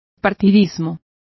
Complete with pronunciation of the translation of partisanship.